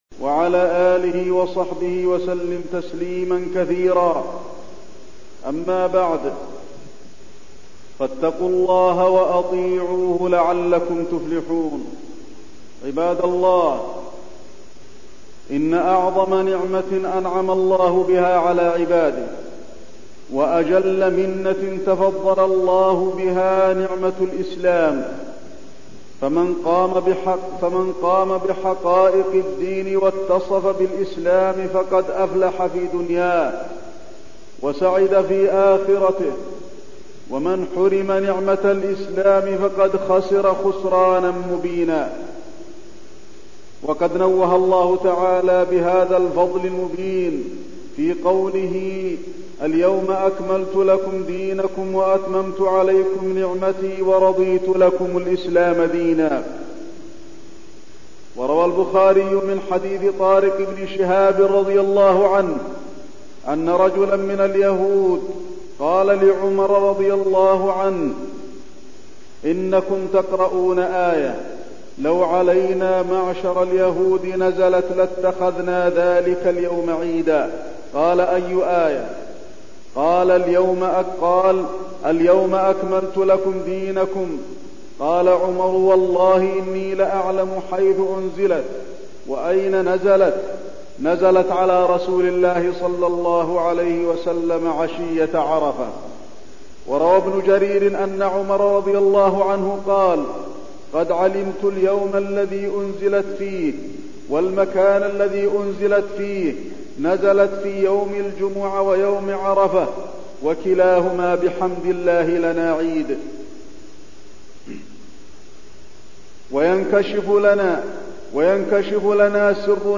تاريخ النشر ٧ ذو الحجة ١٤٠٥ هـ المكان: المسجد النبوي الشيخ: فضيلة الشيخ د. علي بن عبدالرحمن الحذيفي فضيلة الشيخ د. علي بن عبدالرحمن الحذيفي نعمة الإسلام